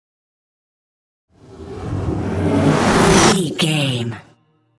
Trailer dramatic raiser
Sound Effects
Atonal
intense
tension
dramatic
riser